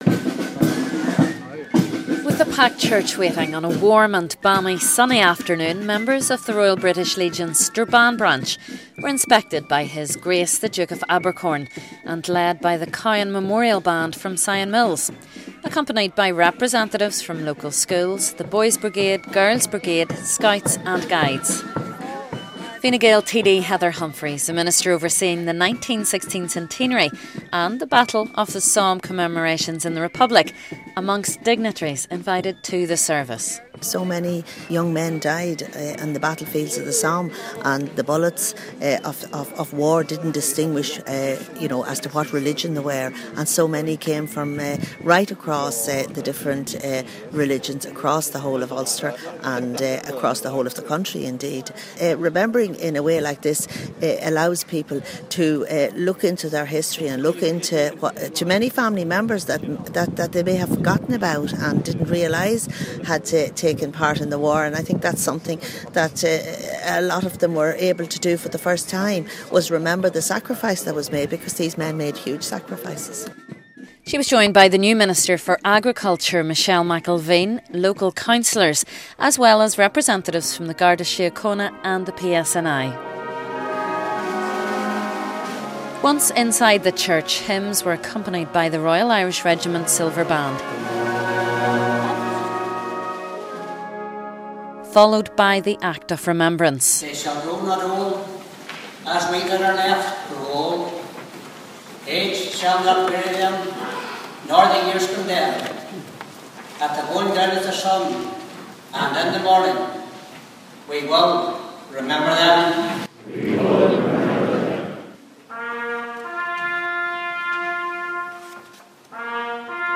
Several hundred people attended a special service of Remembrance for the Centenary of the Battle of The Somme. It was held at Strabane Presbyterian Church and was attended by the Fine Gael Minister Heather Humphreys.